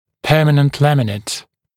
[‘pɜːmənənt ‘læmɪnət][‘пё:мэнэнт ‘лэминэт]постоянный винир (напр. на резцах)